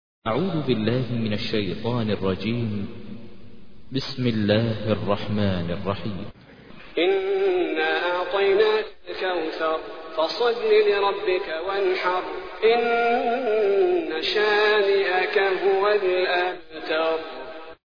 تحميل : 108. سورة الكوثر / القارئ ماهر المعيقلي / القرآن الكريم / موقع يا حسين